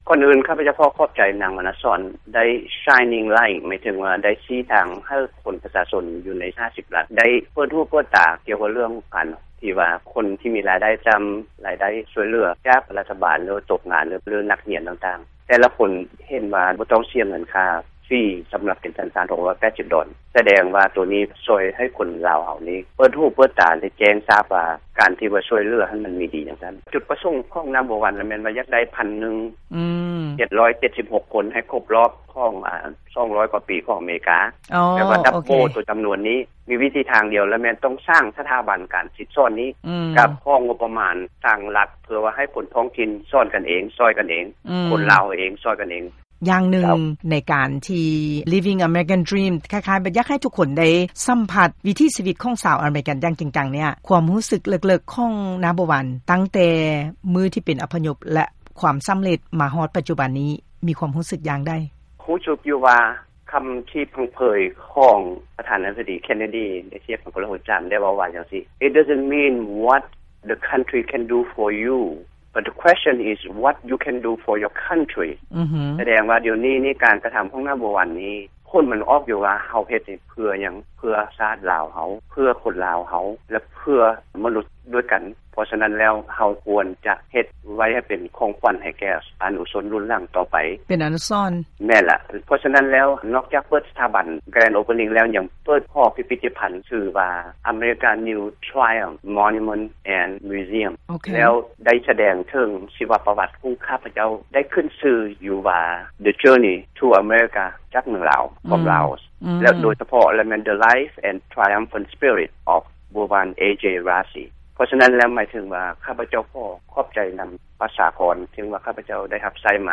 ການສຳພາດ ບຸກຄົນ ທີ່ກ່ຽວຂ້ອງ ໃນການປ່ຽນ ມາຖື ສັນຊາດ ອາເມຣິກັນ